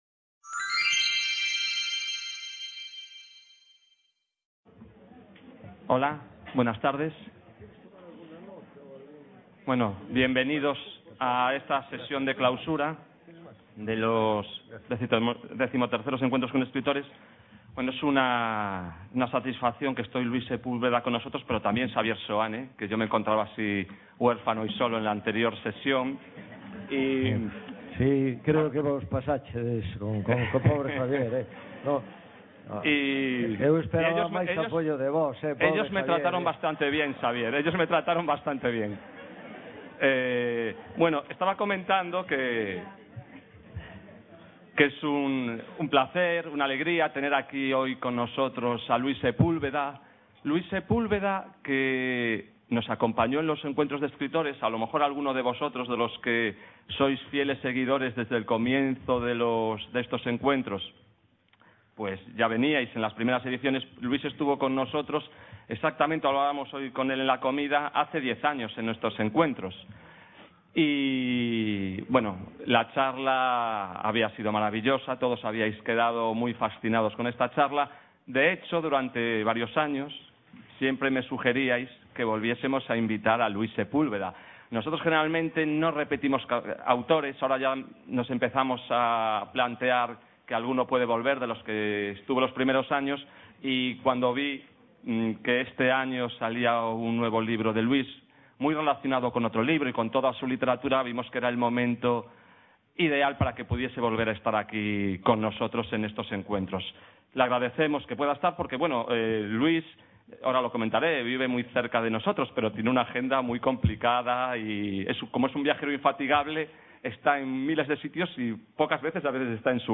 A creación literaria e os seus autores. XIII Encontros con escritores. Con Luis Sepúlveda Description Encuentro con Luis Sepúlveda, escritor chileno, políticamente comprometido que sufrió prisión durante la dictadura de Pinochet y posteriormente abandonó el país.
CA La Coruña (A Coruña) - A creación literaria e os seus autores.